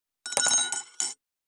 240,食器にスプーンを置く,ガラスがこすれあう擦れ合う音,ポン,ガシャン,ドスン,ストン,カチ,タン,バタン,スッ,サッ,コン,
コップ効果音厨房/台所/レストラン/kitchen物を置く食器